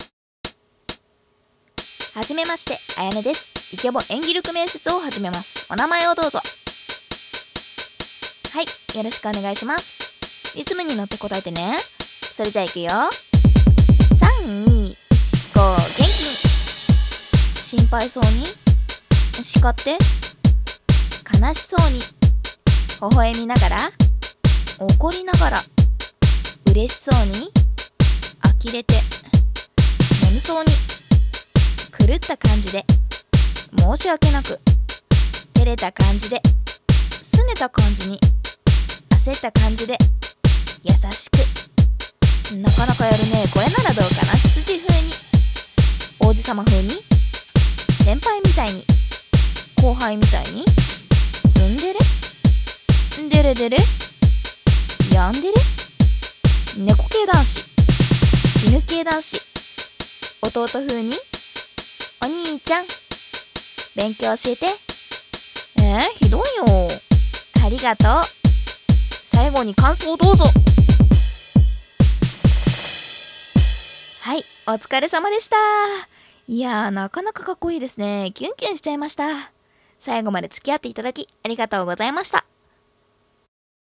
【面接】 イケボ演技力面接！